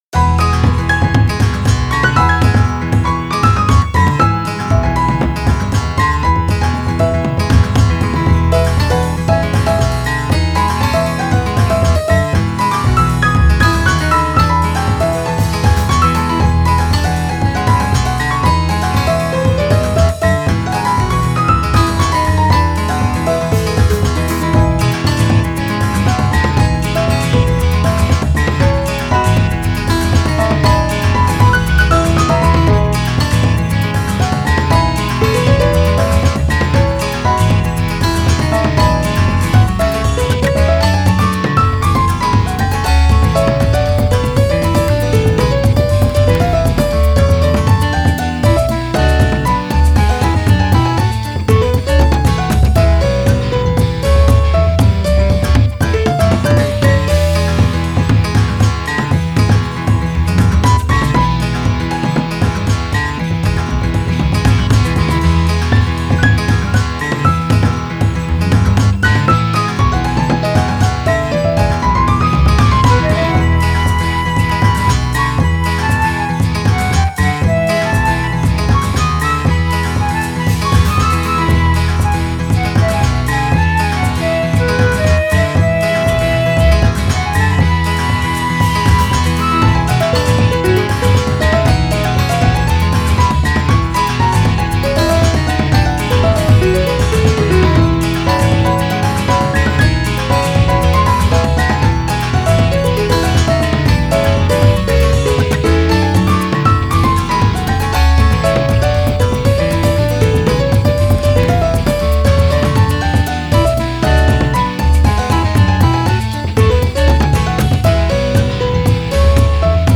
明るい雰囲気の曲です。
草原あたりのイメージです
タグ あかるい 朝